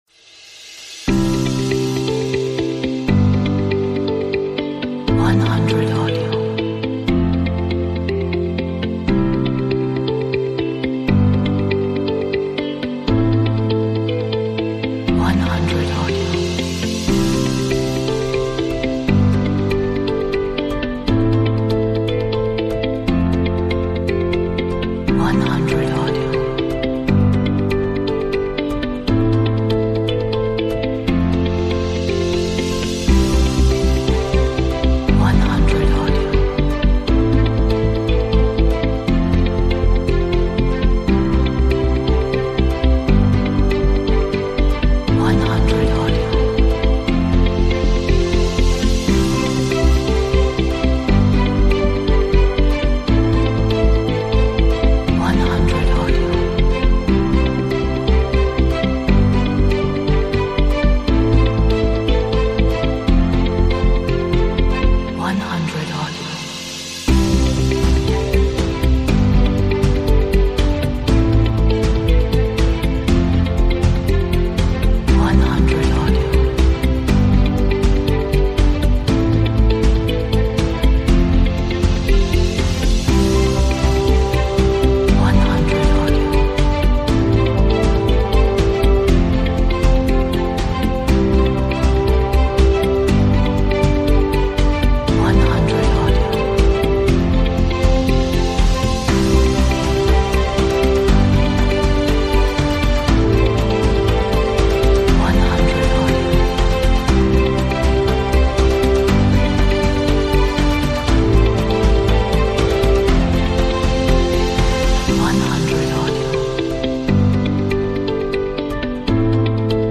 Atmospheric, dramatic, epic,